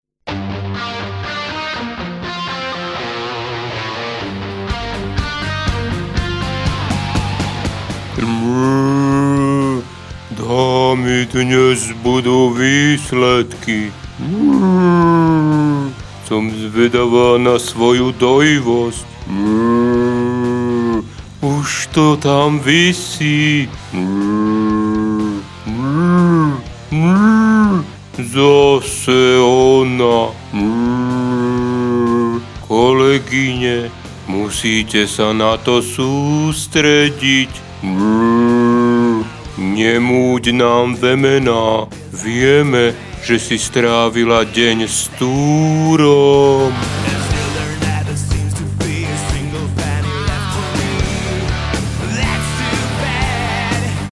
ID jingel 5